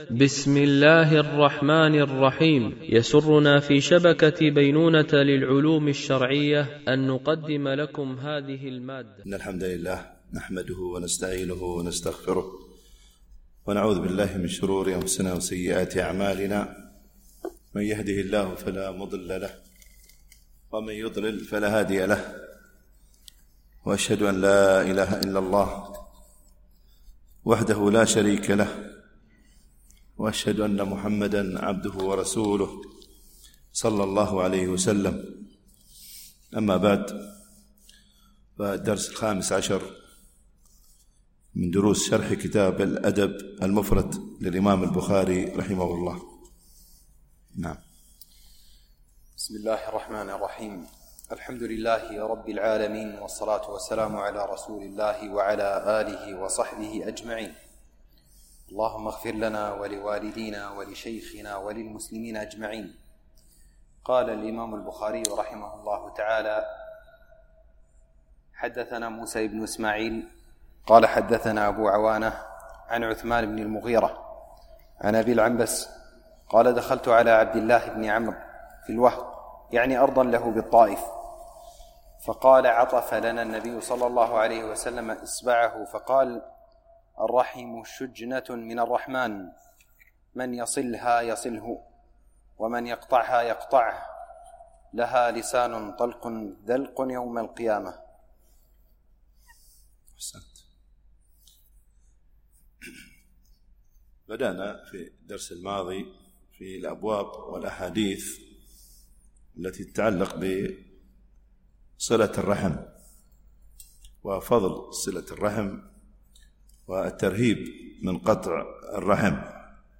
الشرح الثاني للأدب المفرد للبخاري - الدرس15 ( الحديث 54 -57 )